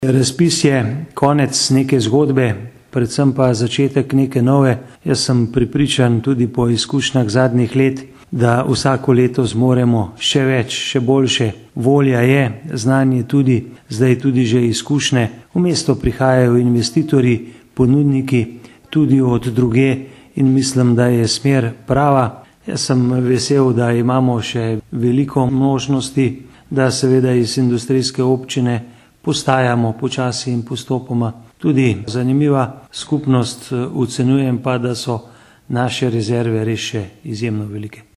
izjava_zupanobcinetrzicmag.borutsajovicorazpisuzagospodarstvo.mp3 (922kB)